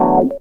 HOUSE 6-L.wav